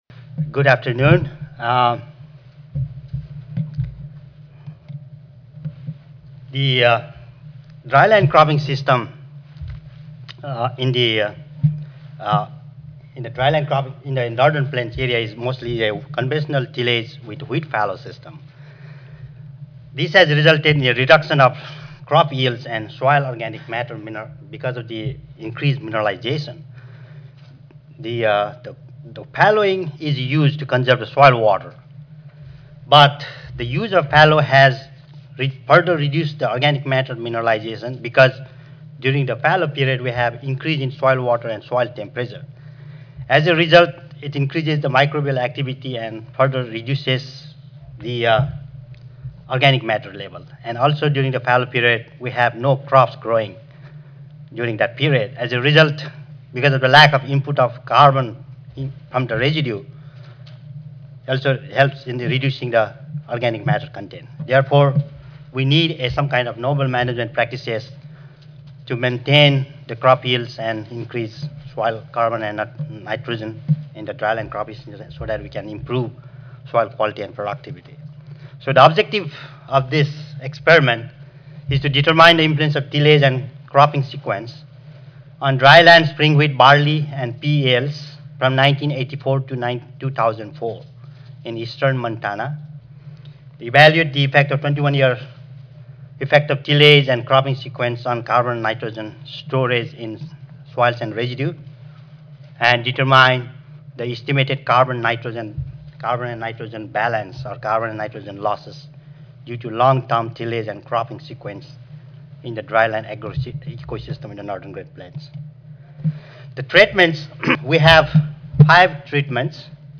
NPARL-USDA-ARS Audio File Recorded presentation